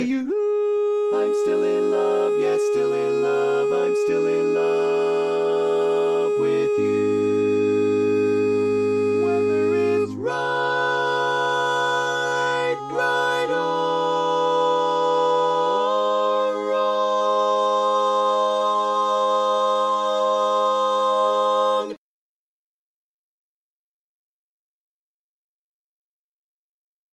Key written in: A♭ Major
How many parts: 4
Type: Barbershop
Learning tracks sung by